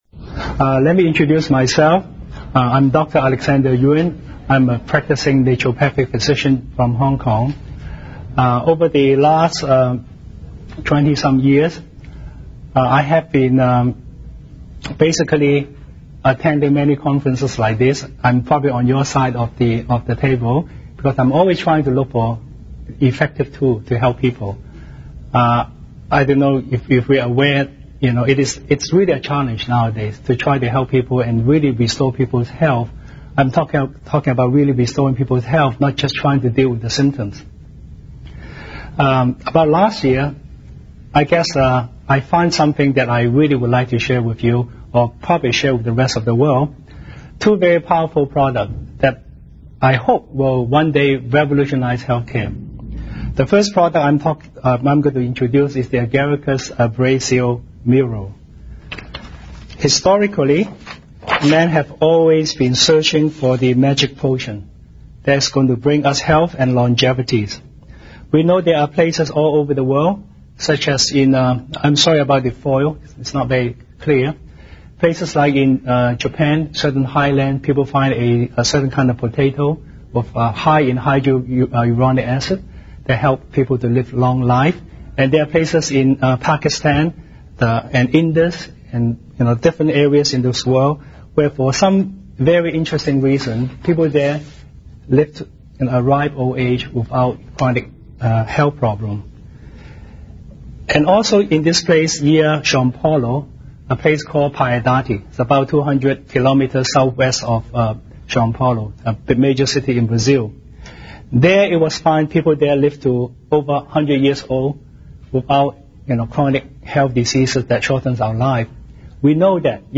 Seminar about Fine Agaricus Mushroom